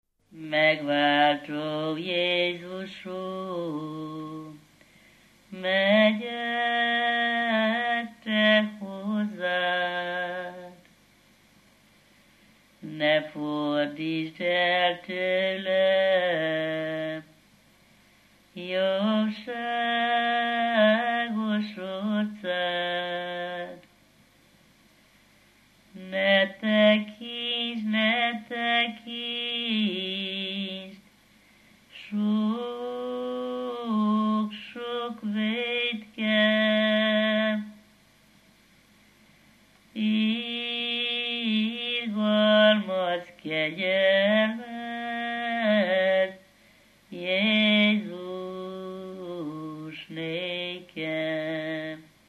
Alföld - Szabolcs vm. - Bököny
ének
Dallamtípus: Lóbúcsúztató - halottas 2; Hol vagy, én szerelmes Jézus Krisztusom
Stílus: 8. Újszerű kisambitusú dallamok